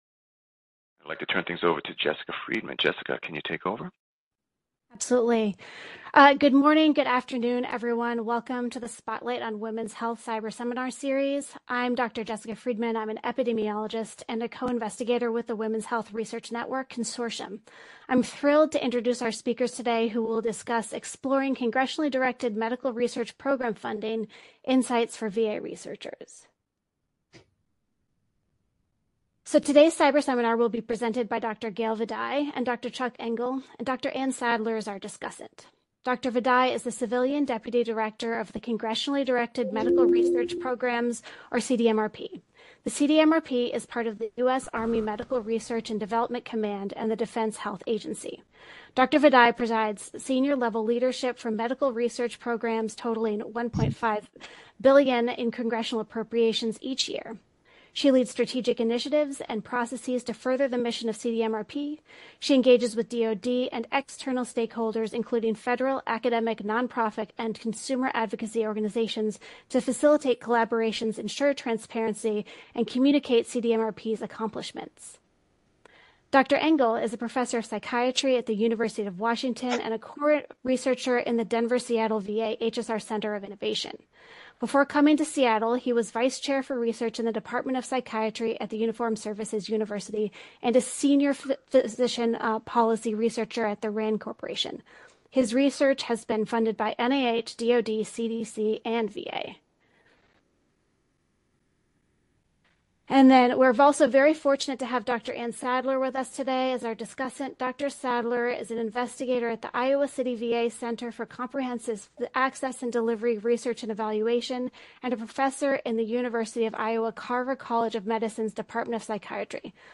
Intended Audience: VA-based researchers and clinicians See also : Outline for VA/CDMRP Funding Talk View archived session video: DOWNLOAD: Request PDF Handout | Audio only (mp3) | transcript